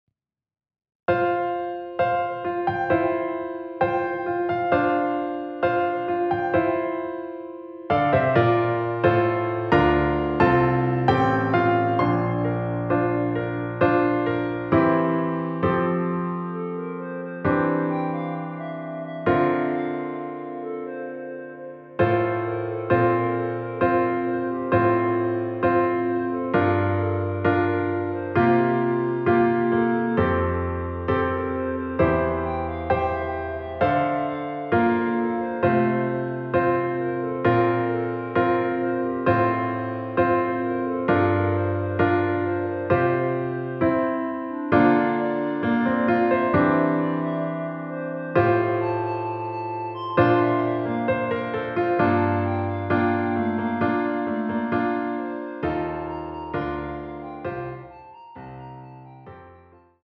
반주가 피아노 하나만으로 되어 있습니다.(미리듣기 확인)
원키에서(+4)올린 피아노 버전 멜로디 포함된 MR입니다.
앞부분30초, 뒷부분30초씩 편집해서 올려 드리고 있습니다.